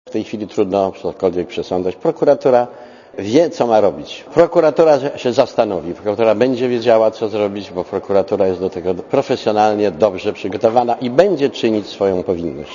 Posłuchaj komentarza Andrzeja Kalwasa